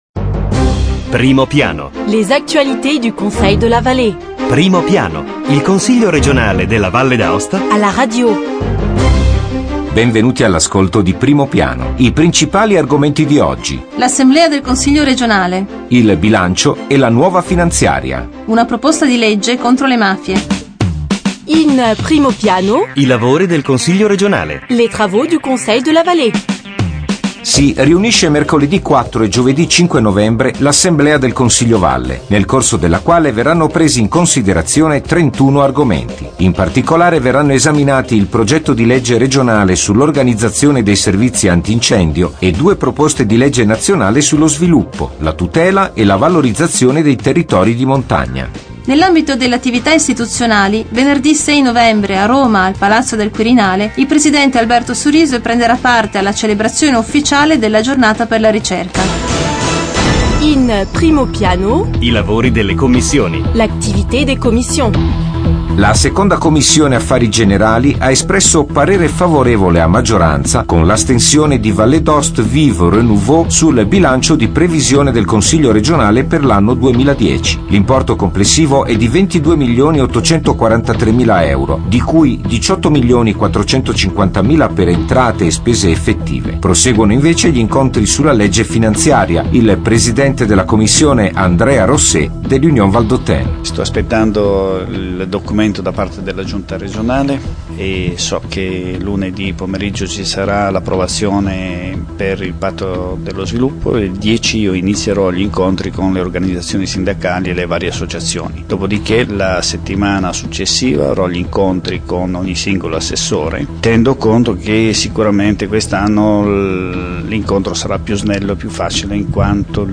Eventi e ricorrenze Documenti allegati Dal 3 novembre 2009 al 10 novembre 2009 Primo Piano Il Consiglio regionale alla radio: approfondimento settimanale sull'attivit� politica, istituzionale e culturale dell'Assemblea legislativa. Questi gli argomenti del nuovo appuntamento con Primo Piano: - Adunanza del Consiglio regionale; - Lavori delle Commissioni consiliari: intervista con il Presidente della II Commissione "Affari generali", Andrea Rosset e con il Consigliere Alberto Bertin. Scopriremo poi gli appuntamenti e le iniziative istituzionali previsti per la settimana.